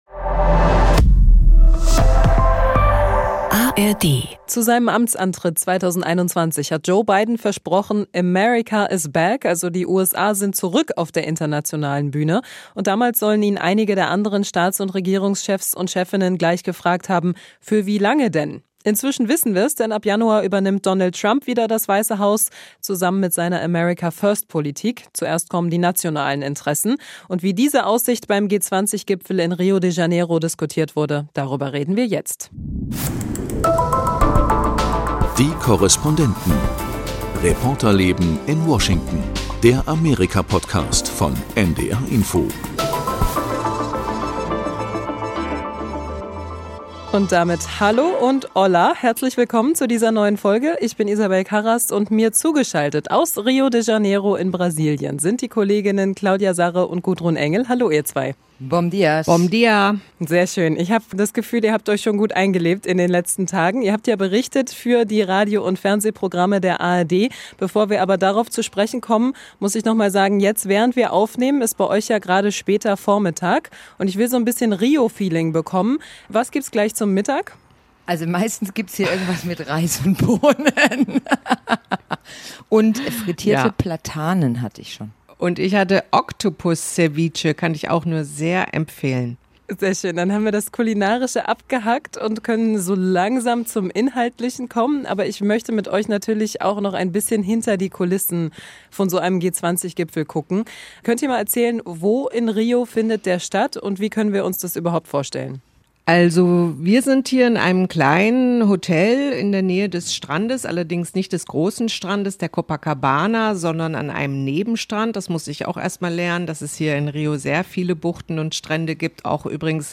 Die ARD-Reporterinnen im Studio Washington berichten zusammen mit ihren Kollegen aus New York und Los Angeles, wie sie das Land erleben. Sie sprechen mit Amerikanern und hören, was sie antreibt. Und sie versuchen zu klären, was hinter den Nachrichten der Woche steckt.